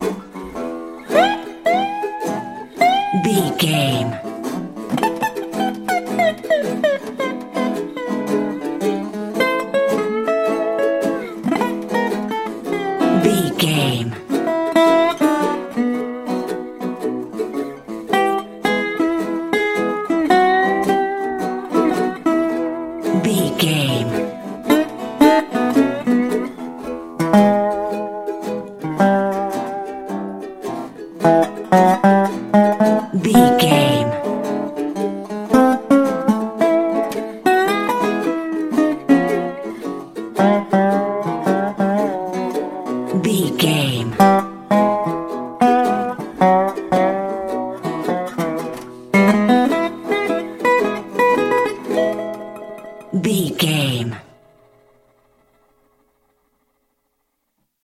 Ionian/Major
acoustic guitar
electric guitar
ukulele
dobro
slack key guitar